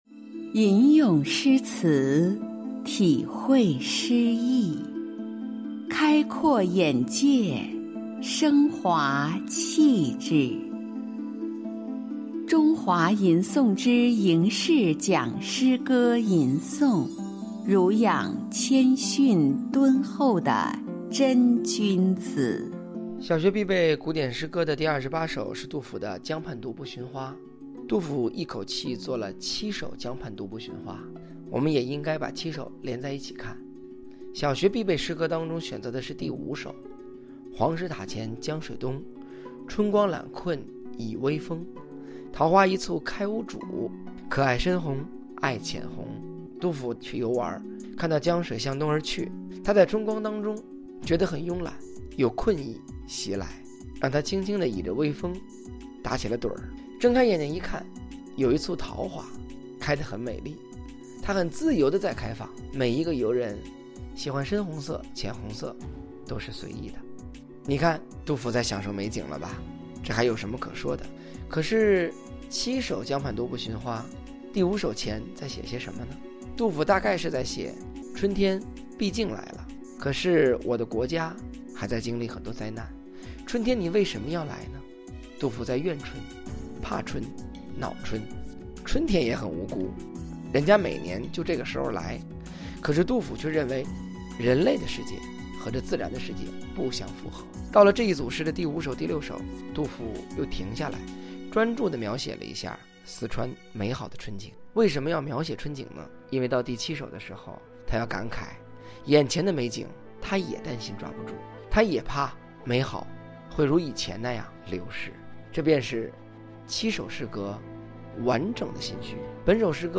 小28 吟诵书写杜甫《江畔独步寻花》其五 / 四六文摘